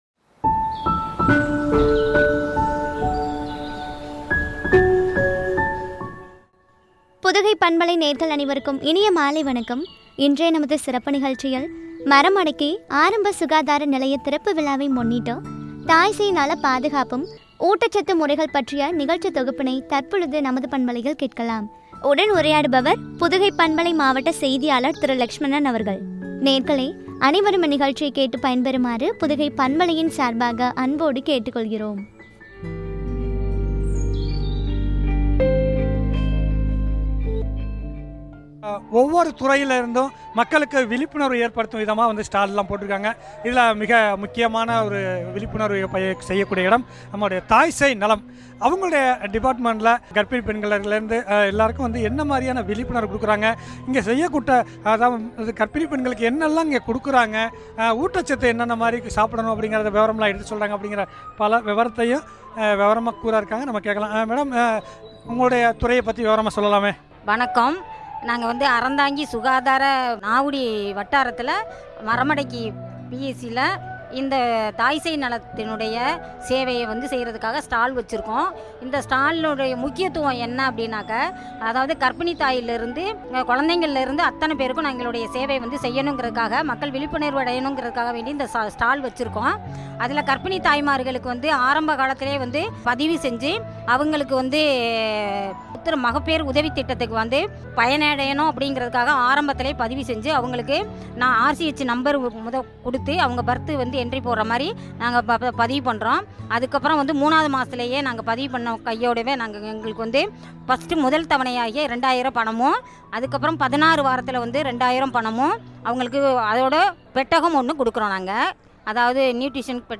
மறமடக்கி, ஆரம்ப சுகாதார நிலைய திறப்பு விழா நிகழ்ச்சியின் தொகுப்பு, தாய் சேய் நல பாதுகாப்பும், ஊட்டச்சத்து முறைகளும், பொதுமக்களுக்கான விழிப்புணர்வு உரையாடல்.